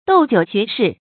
斗酒學士 注音： ㄉㄡˇ ㄐㄧㄨˇ ㄒㄩㄝˊ ㄕㄧˋ 讀音讀法： 意思解釋： 指酒量大的文士或名臣。